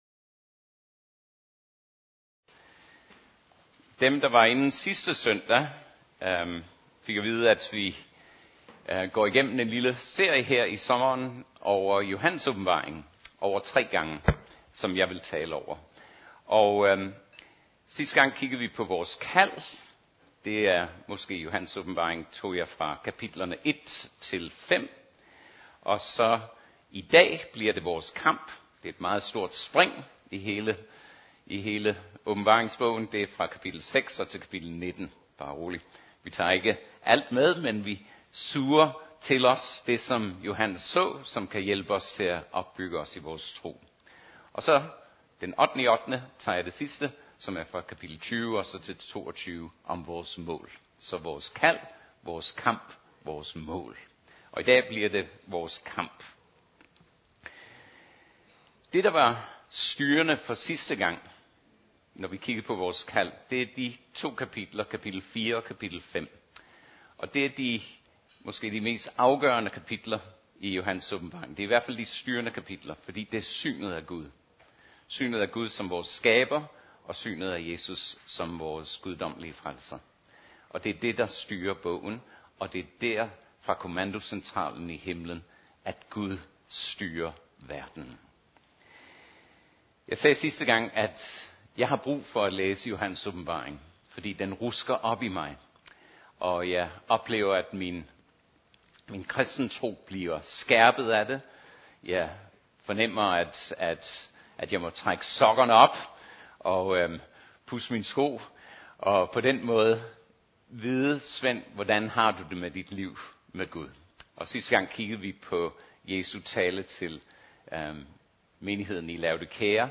Type af tale Prædiken